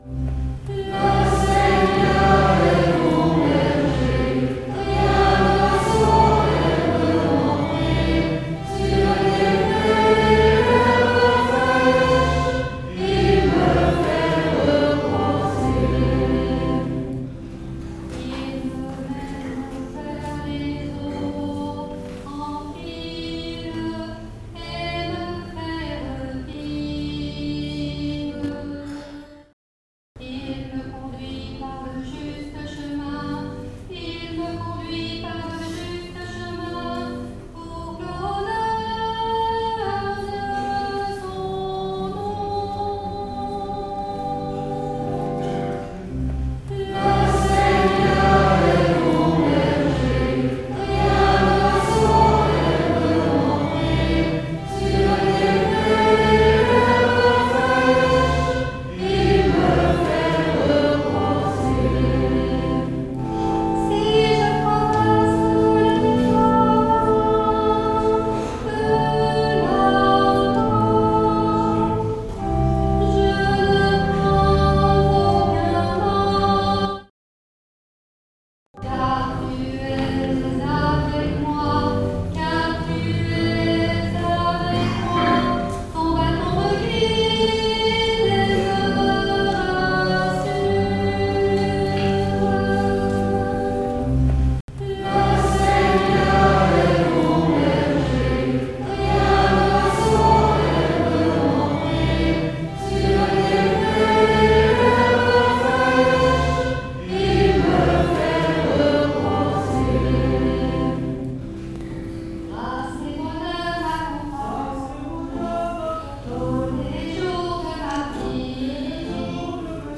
♫ Enregistrement du 26 novembre 2023 en l'église Notre-Dame de l'Assomption